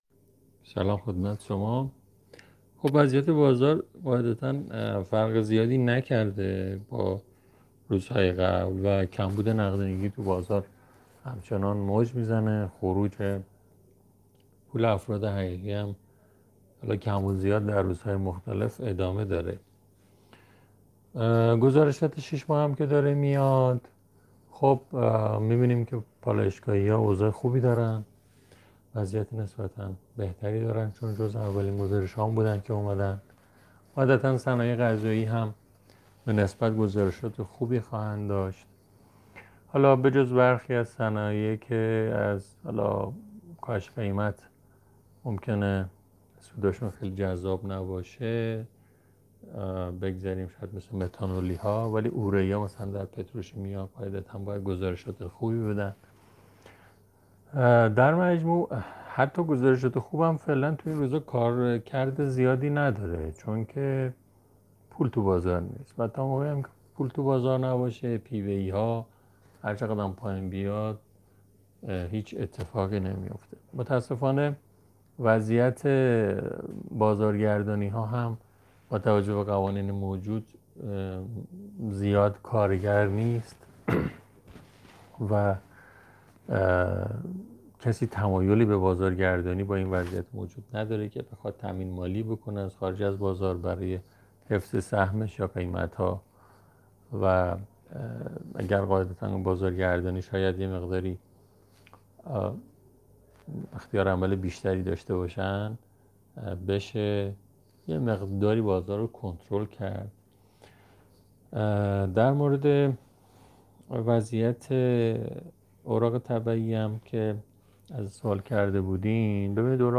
یک کارشناس بازار سرمایه به بررسی روند معاملات روز بازار سرمایه پرداخت.